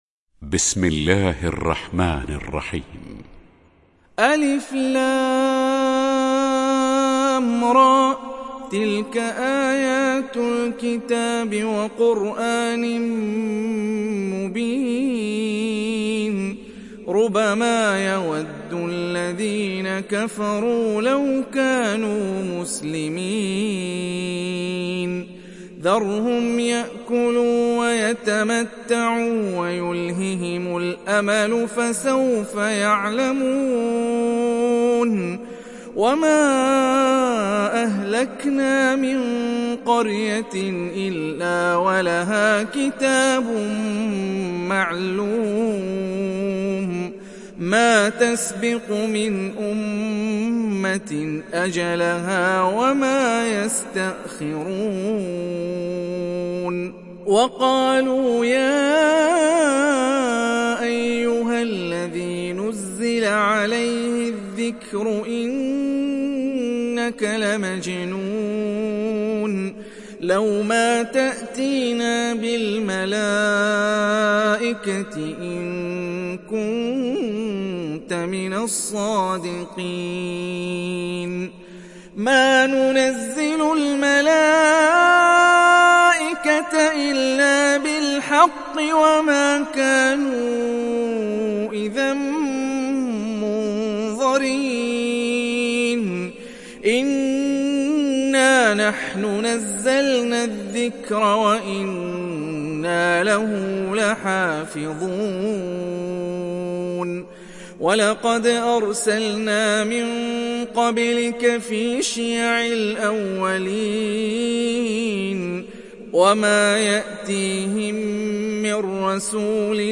دانلود سوره الحجر mp3 هاني الرفاعي (روایت حفص)